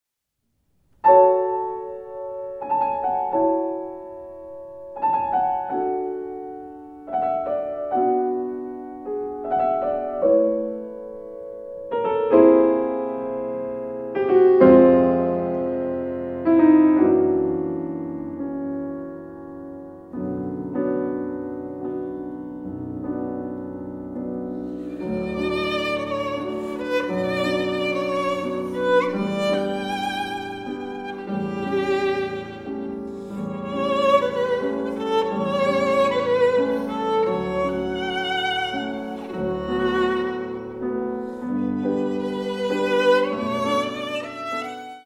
for violin and piano
violin
piano
in B Minor